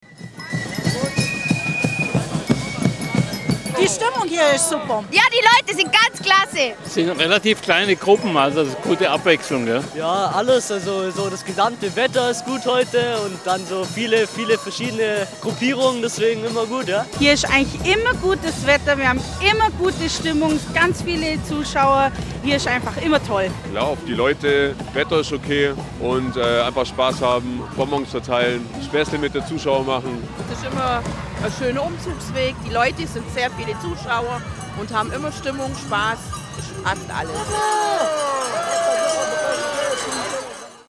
Närrisches Treiben beim Narrenumzug in Amtzell (Kreis Ravensburg).